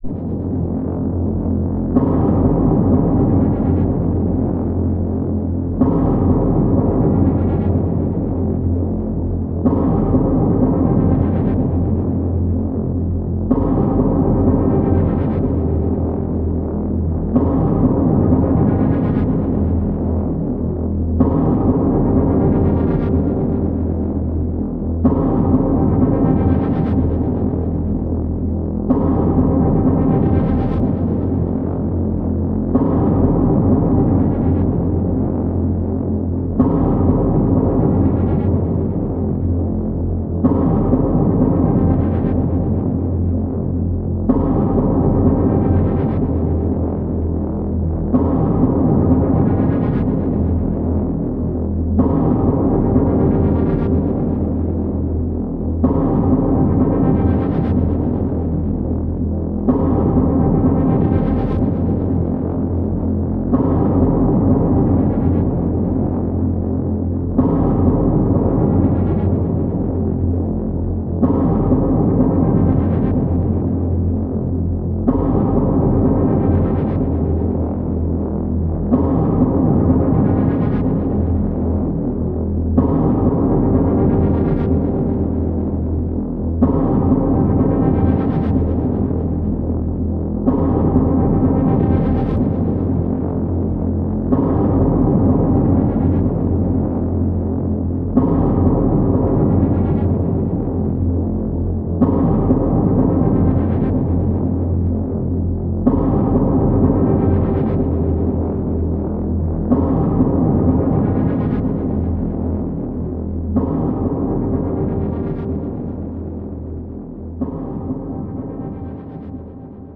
• Category: Sleep